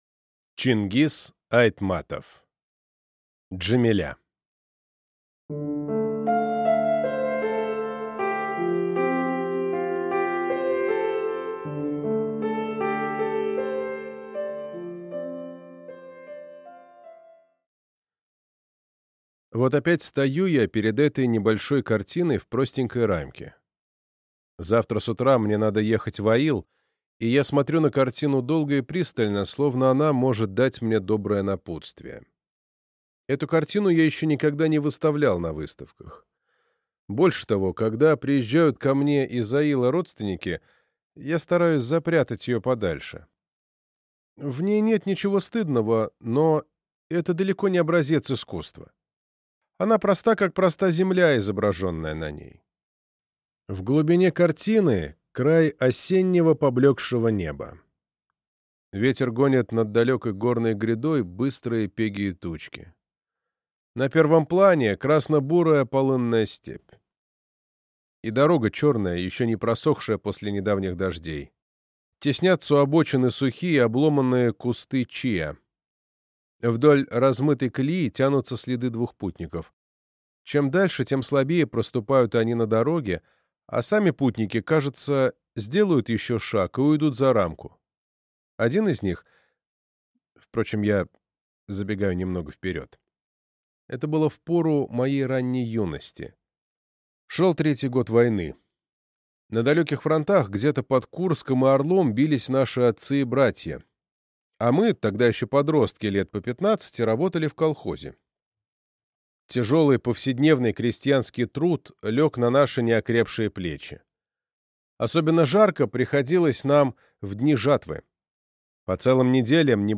Аудиокнига Джамиля | Библиотека аудиокниг